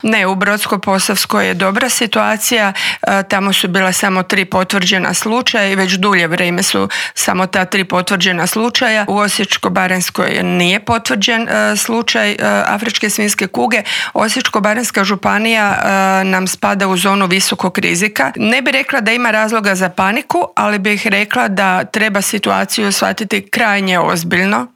Socio-ekonomske posljedice su ogromne, a o tome kako izaći na kraj s ovom bolesti koja ne pogađa ljude u medicinskom, ali definitivno da u ekonomskom smislu, razgovarali smo u Intervjuu tjedna Media servisa s ravnateljicom Uprave za veterinarstvo i sigurnost hrane Tatjanom Karačić.